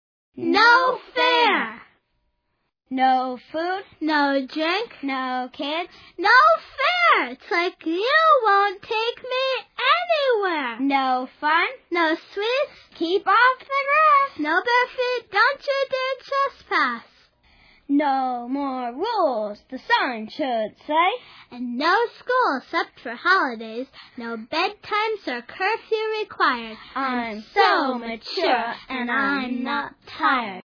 It's a collection of poems performed by kids, but For Kids By Kids is good for everybody big and little. Listeners will grin from ear to ear over the mispronounced words, the imaginative language and the expressiveness of each kid's unique voice.